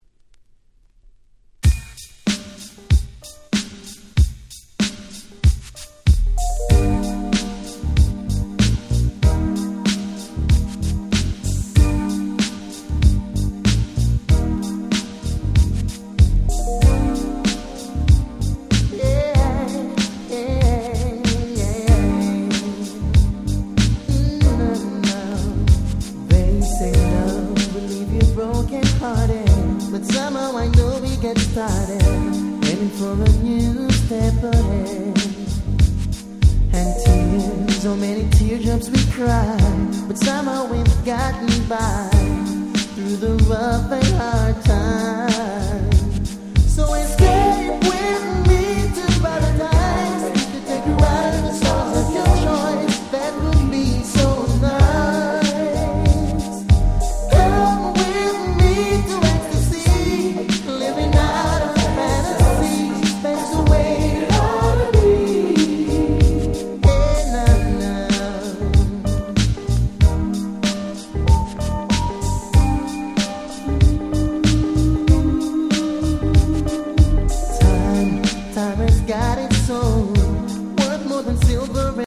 96' Very Nice Reggae R&B !!